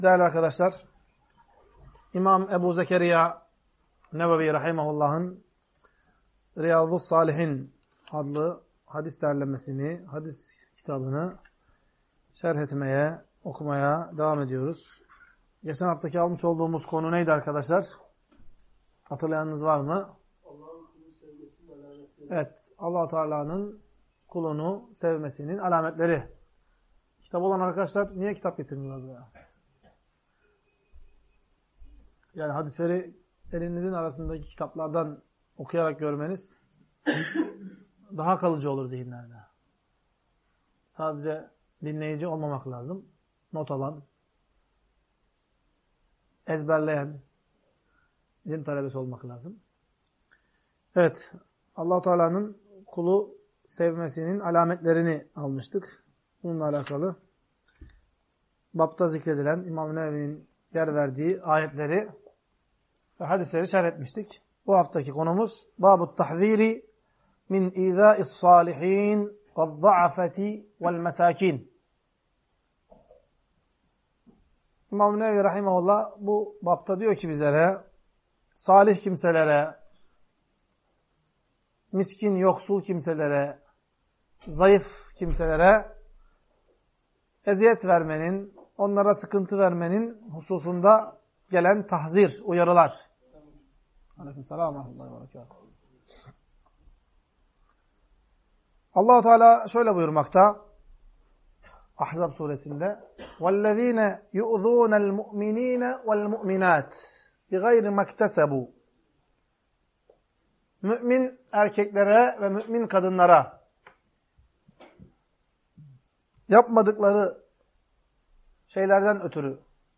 Ders - 48.